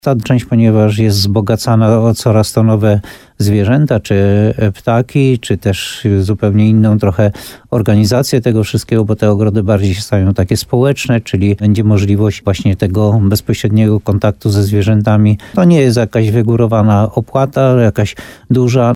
W tym sezonie wejście do muszyńskich ogrodów będzie biletowane. Chodzi tylko o tzw. ogrody żywe, czyli te ze zwierzętami – mówił w programie Słowo za Słowo na antenie RDN Nowy Sącz, burmistrz Muszyny Jan Golba.